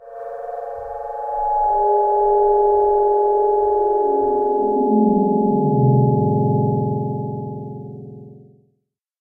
Minecraft Version Minecraft Version 25w18a Latest Release | Latest Snapshot 25w18a / assets / minecraft / sounds / ambient / cave / cave5.ogg Compare With Compare With Latest Release | Latest Snapshot
cave5.ogg